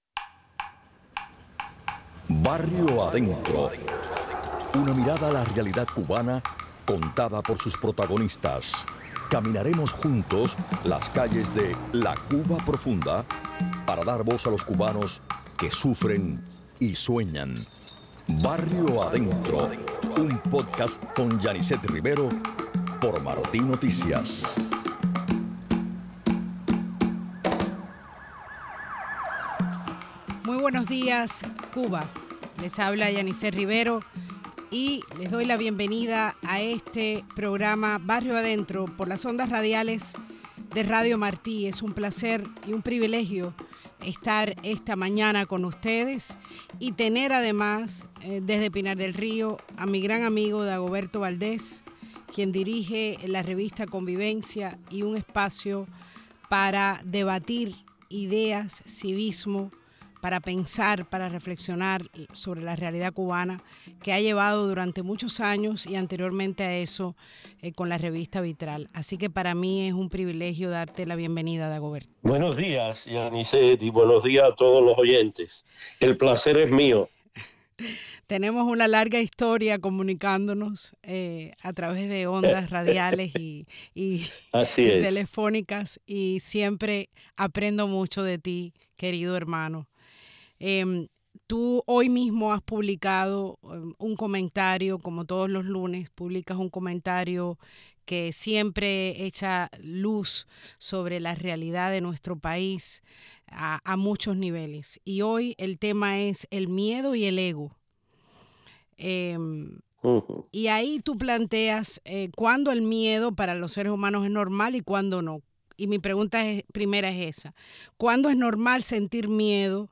Hoy conversamos con el líder civil cubano